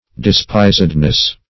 Despisedness \De*spis"ed*ness\, n. The state of being despised.